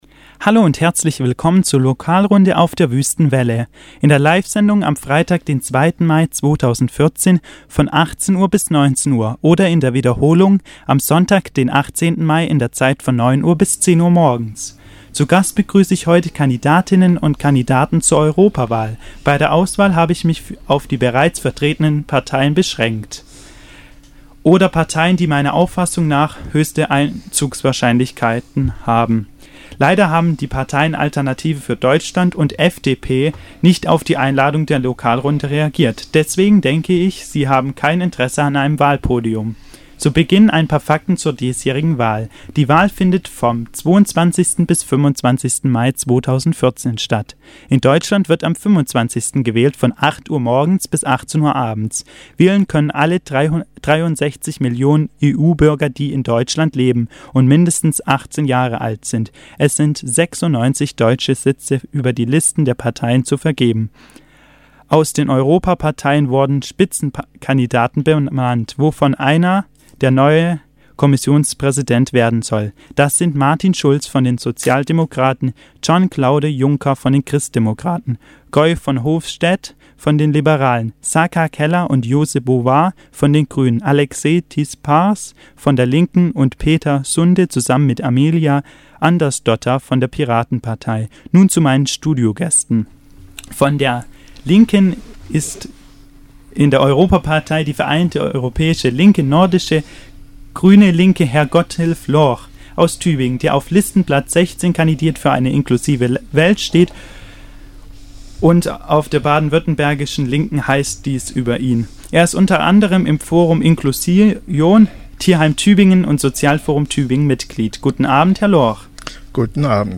- Evelyne Gebhardt, MdEP aus Hohenlohe (SPD)